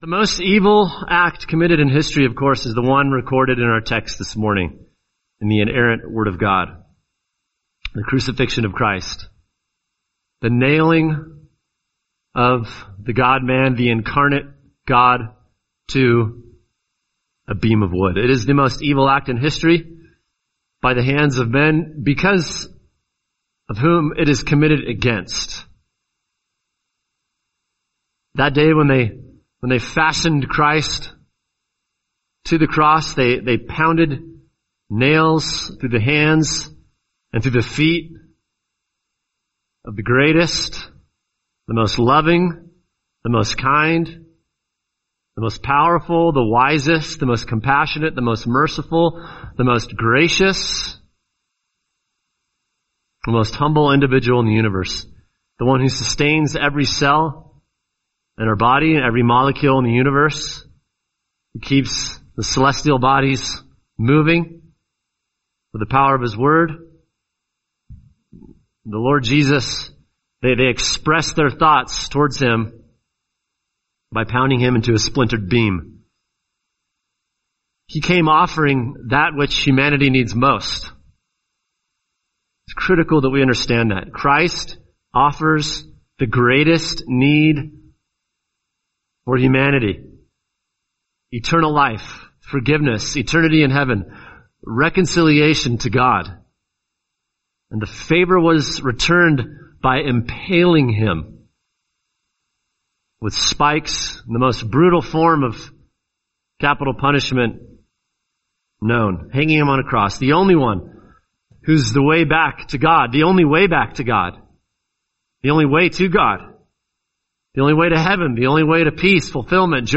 [sermon] Matthew 27:35-38 The Glory of Christ in His Crucifixion | Cornerstone Church - Jackson Hole